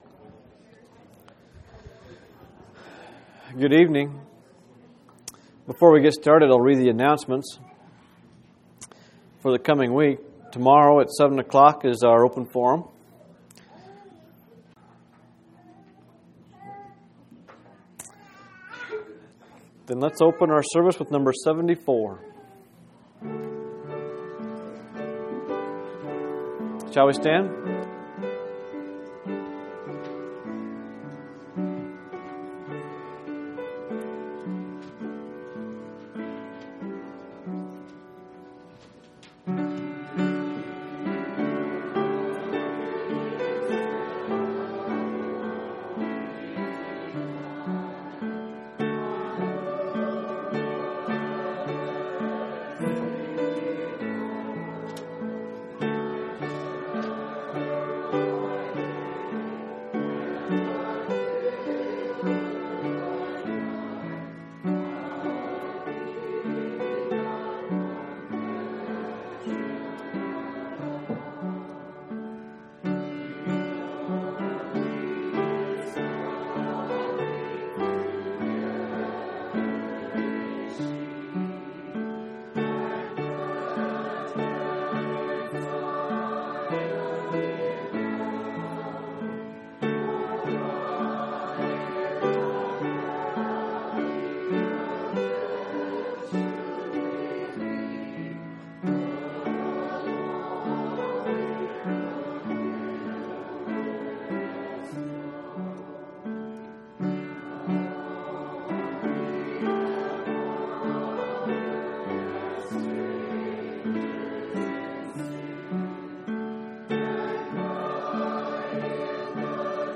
4/25/2004 Location: Phoenix Local Event